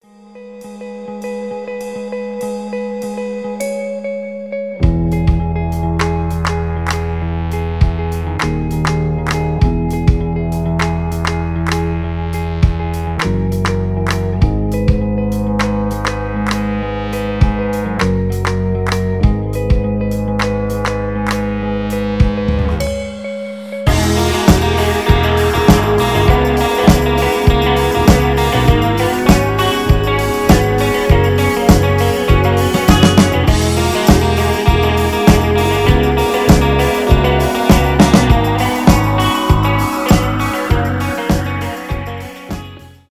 рок , indie rock , без слов , альтернатива